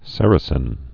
(sĕrĭ-sĭn)